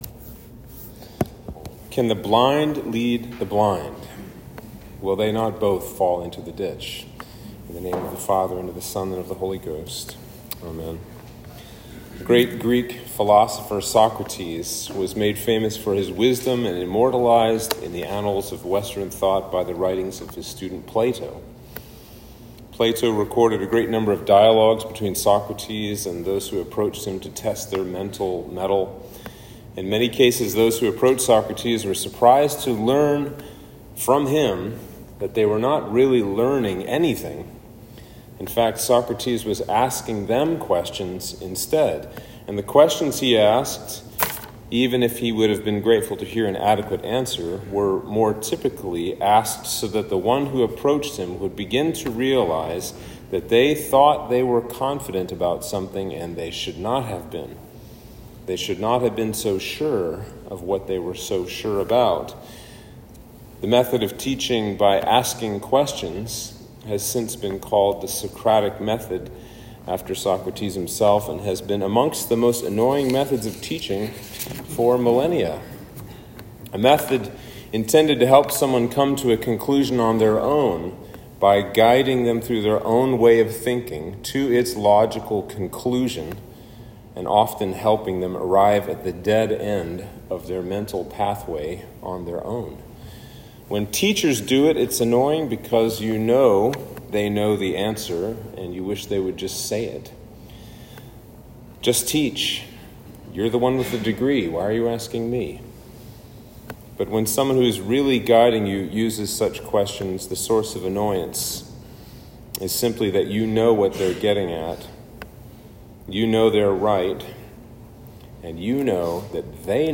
Sermon for Trinity 4